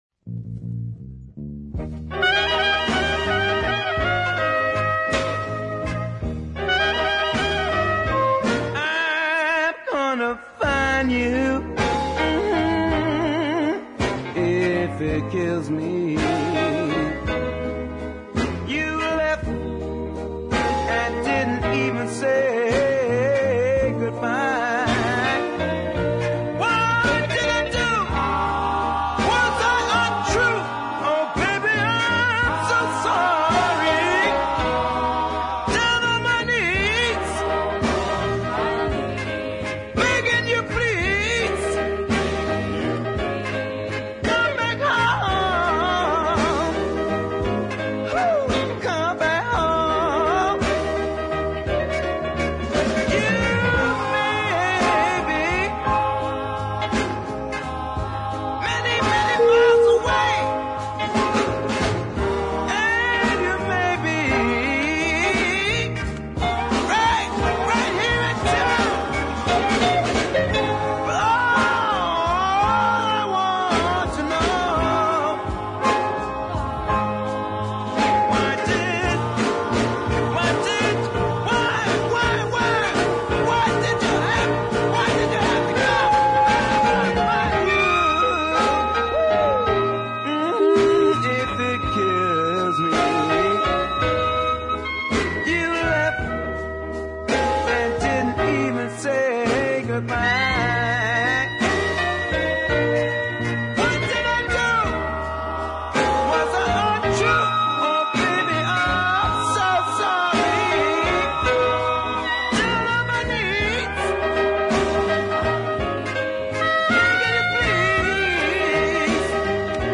blue tinged ballad